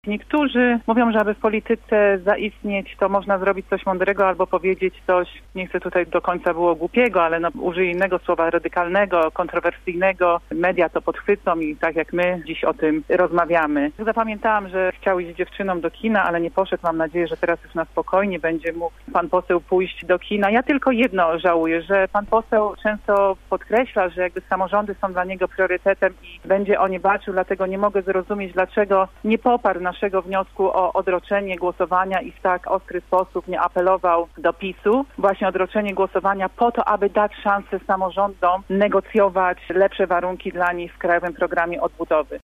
Katarzyna Osos była gościem Rozmowy po 9.